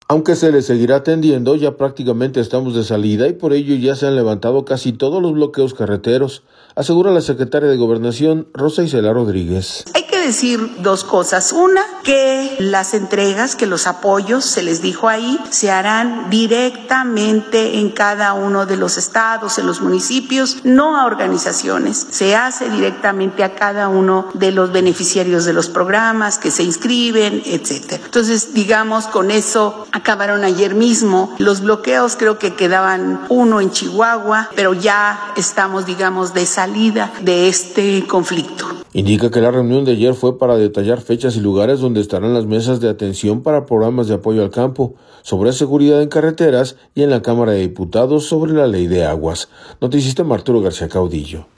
Aunque se les seguirá atendiendo, ya prácticamente estamos de salida y por ello ya se han levantado casi todos los bloqueos carreteros, asegura la secretaria de Gobernación, Rosa Icela Rodríguez.